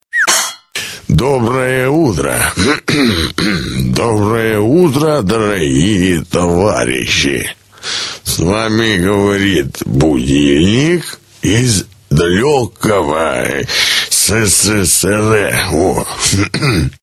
Главная » Рингтоны » Рингтоны на будильник